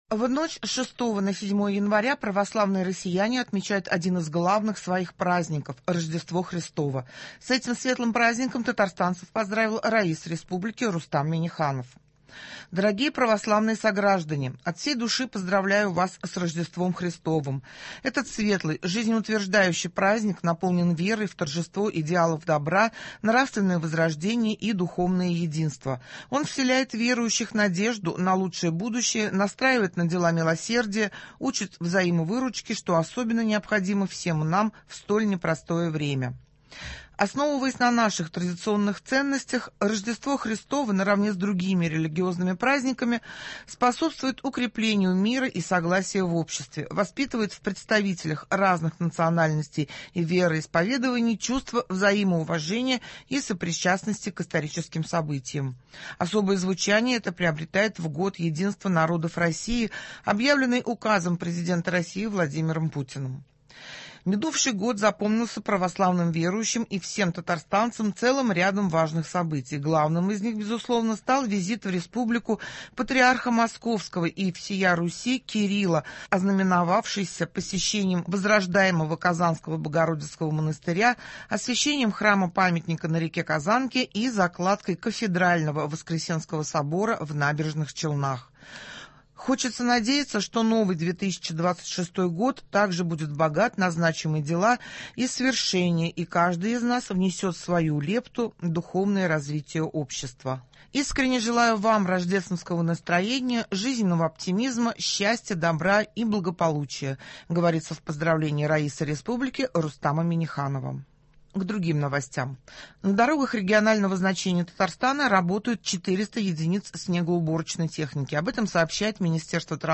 Утренний выпуск.